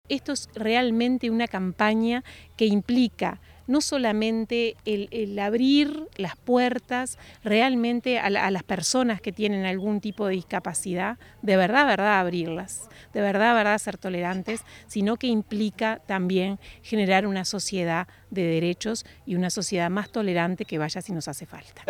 directora_general_de_desarrollo_humano_gabriela_garrido.mp3